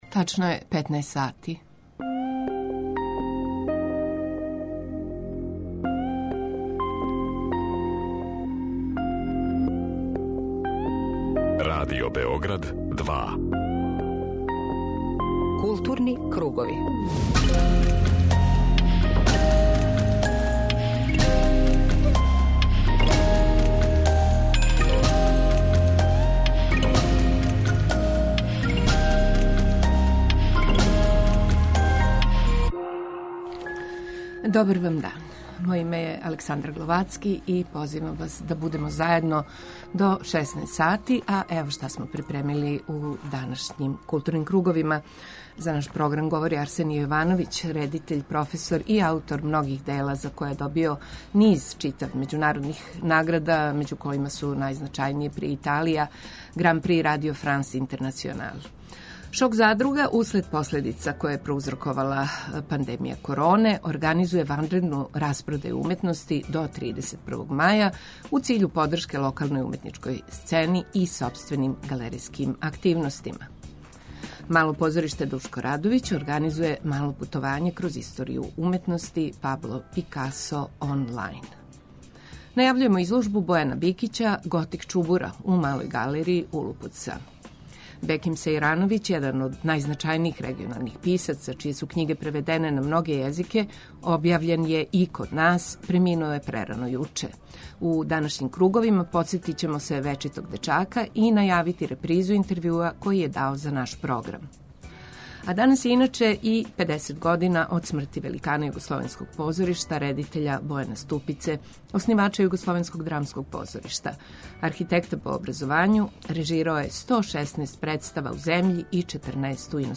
преузми : 19.56 MB Културни кругови Autor: Група аутора Централна културно-уметничка емисија Радио Београда 2.